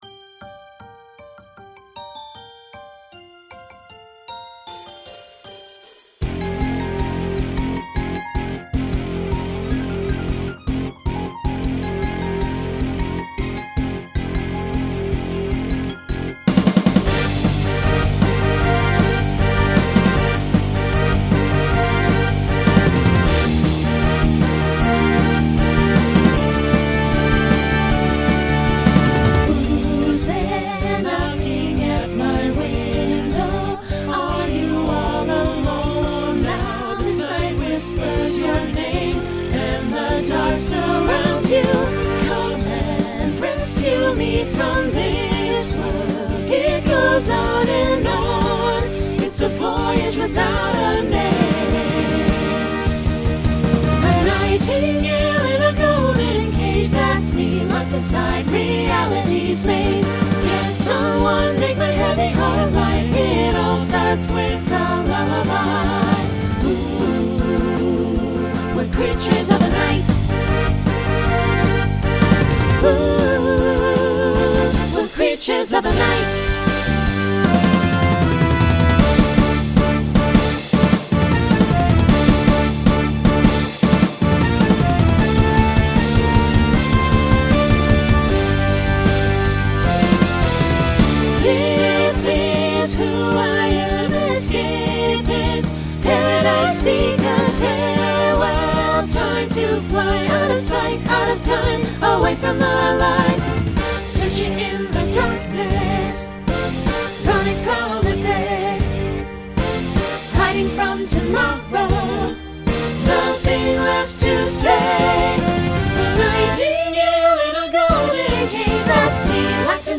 New SSA voicing for 2024.
Genre Rock
Voicing SATB , SSA